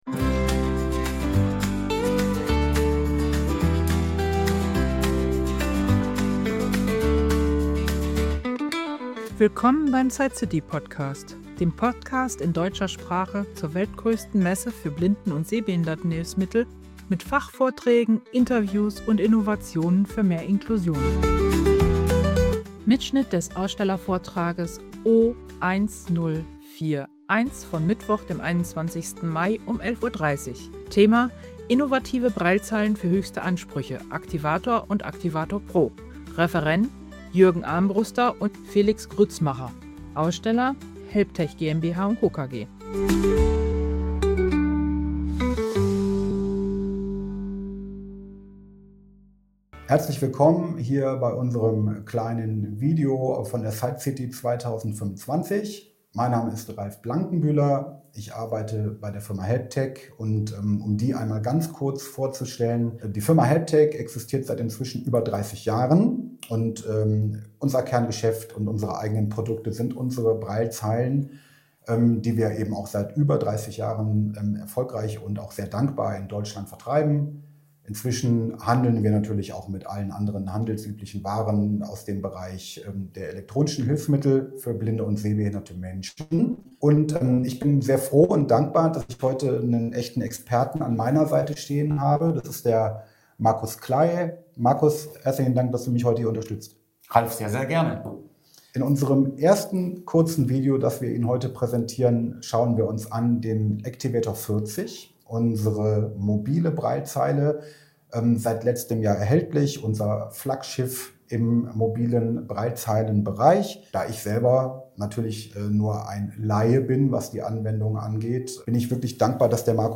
Mitschnitt des Ausstellervortrags O1041 und zweier ergänzender Produktgespräche von der SightCity 2025: Der mobile Activator 40 sowie die Arbeitsplatz-Braillezeilen Activator Pro 64 und 80 stehen im Mittelpunkt.